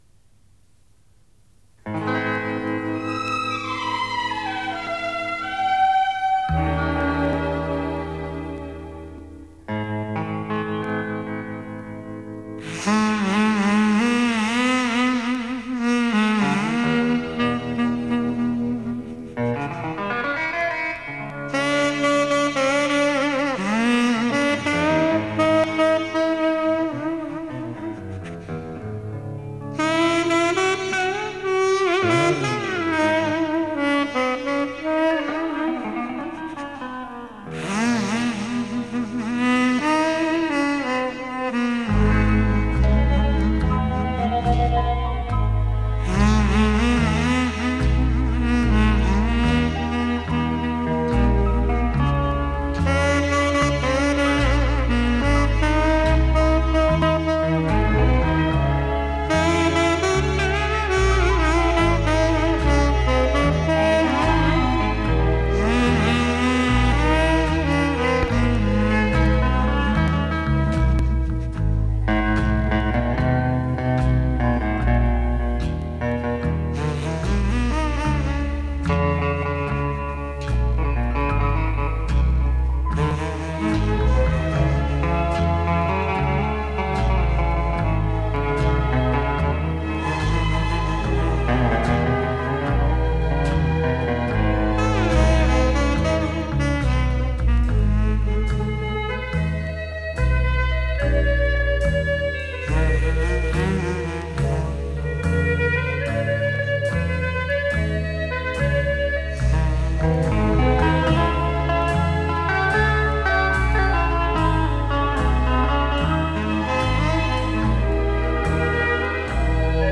Japan sax - с винила 70-х